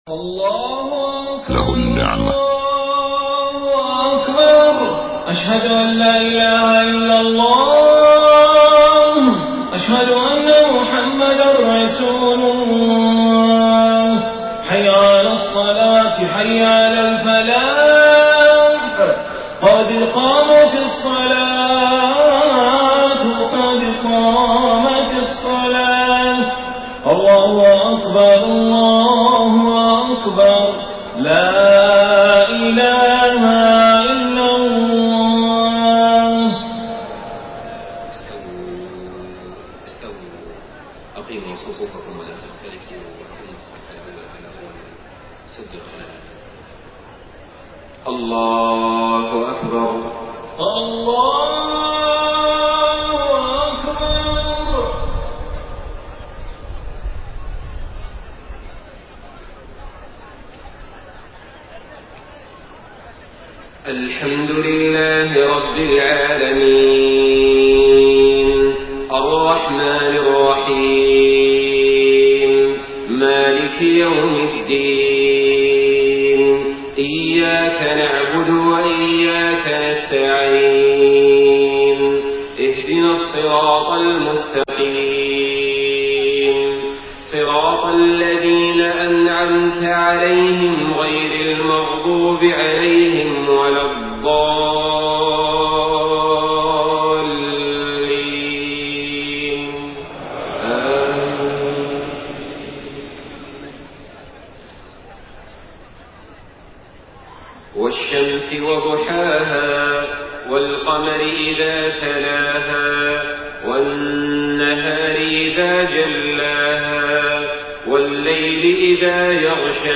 صلاة العشاء 11 ربيع الأول 1431هـ سورتي الشمس و التين > 1431 🕋 > الفروض - تلاوات الحرمين